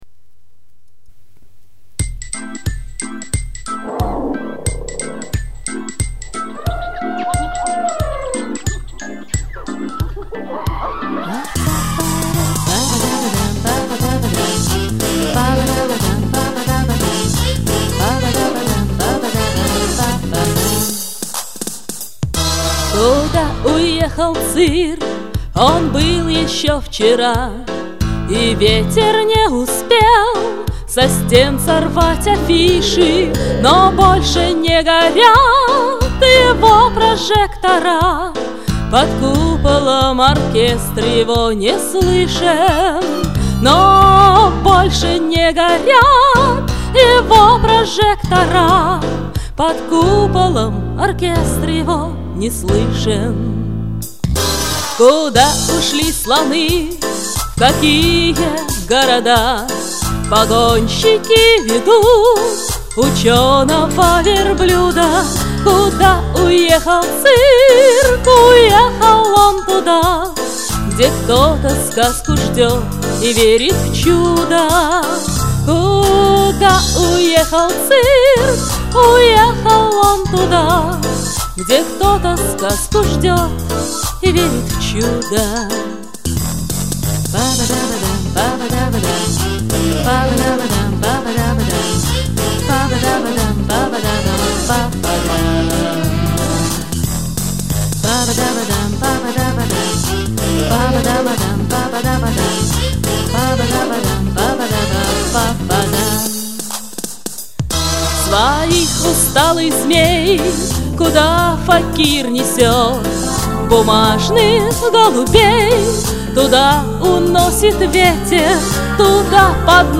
Понравились обе исполнительницы!